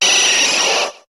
Cri de Nosferalto dans Pokémon HOME.